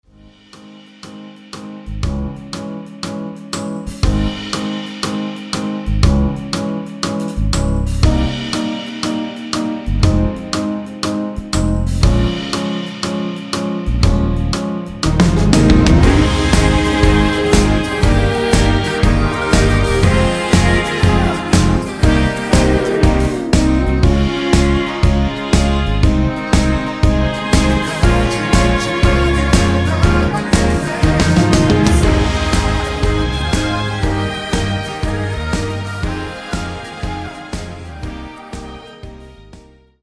원키에서(-2)내린 코러스 포함된 MR입니다.
Ab
앞부분30초, 뒷부분30초씩 편집해서 올려 드리고 있습니다.
중간에 음이 끈어지고 다시 나오는 이유는